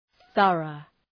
{‘ɵʌrəʋ}
thorough.mp3